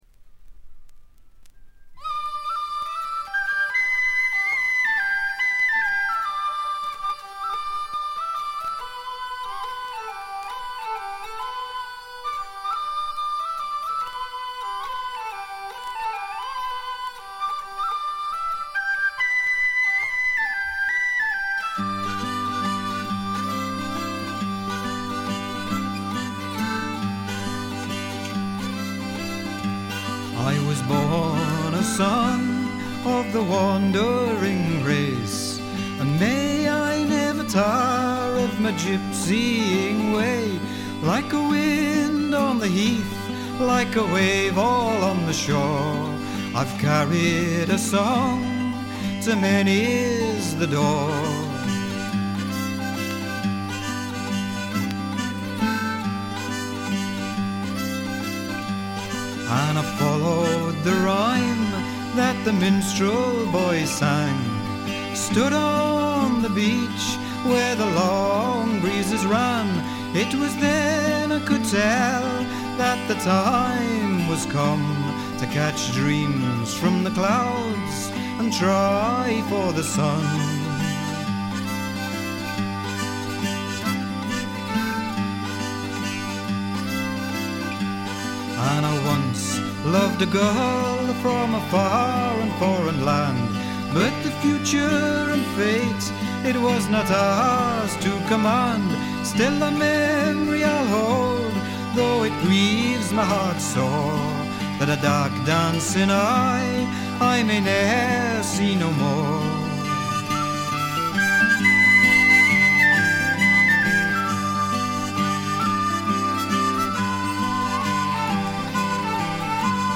ホーム > レコード：英国 フォーク / トラッド
滋味あふれる、実に味わい深い歌の数々。
試聴曲は現品からの取り込み音源です。
Vocals, Guitar, Hurdy Gurdy, Dulcimer